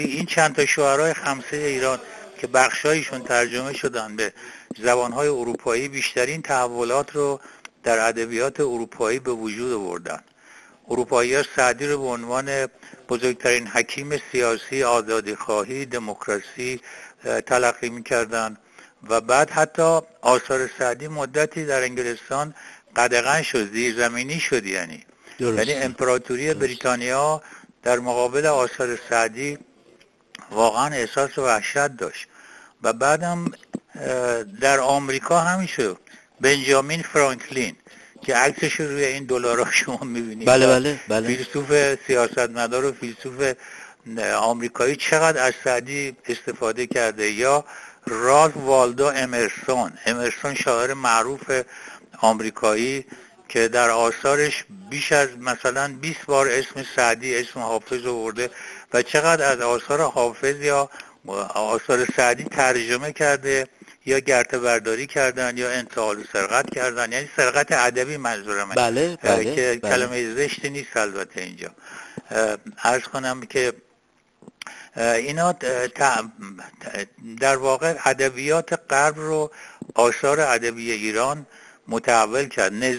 روزگار و زمانه سعدی در گفت‌وگوی ایکنا